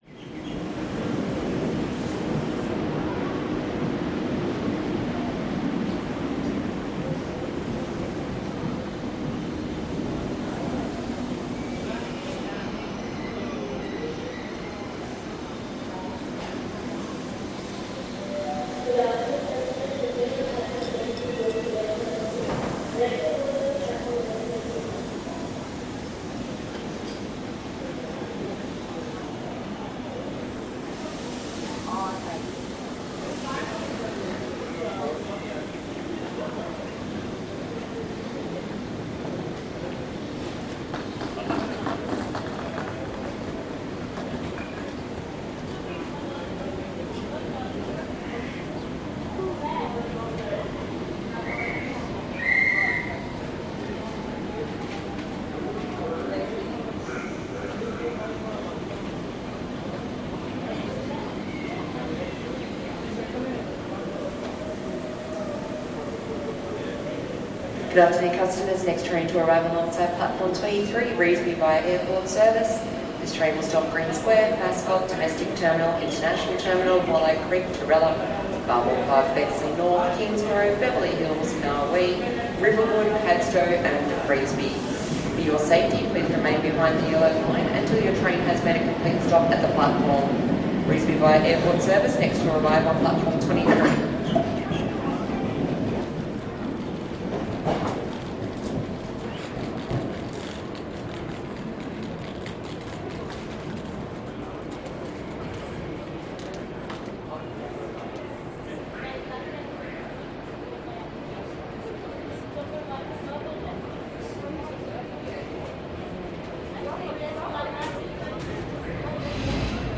Звуки железнодорожного вокзала
Атмосфера Центрального вокзала Сиднея снаружи, люди идут и разговаривают, поезда на платформе 23, объявления через динамики